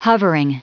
Prononciation du mot hovering en anglais (fichier audio)
Prononciation du mot : hovering